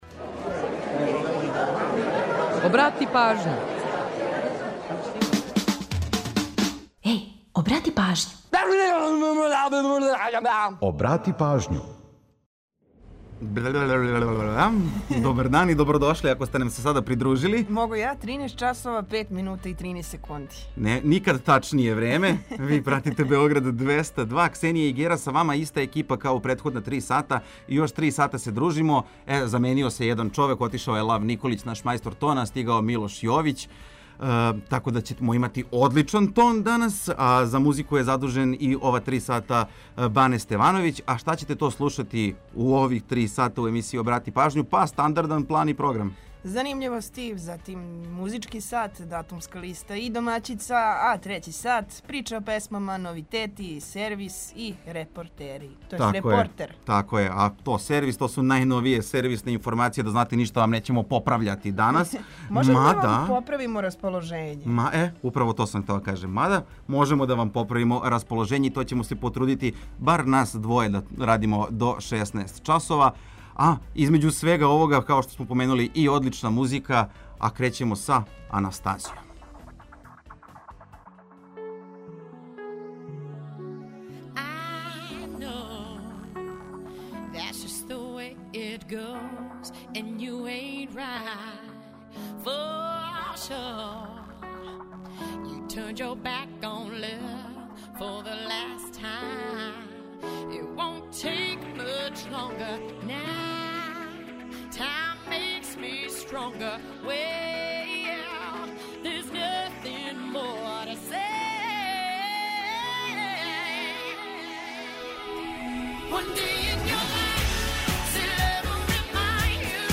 У данашњој емисији поред различитих занимљивости слушаћете и датумске песме које нас подсећају на рођендане музичара, као и годишњице објављивања албума, синглова и других значајних догађаја из историје попа и рокенрола.
Ту је и пола сата резервисано само за музику из Србије и региона, а упућујемо вас и на нумере које су актуелне.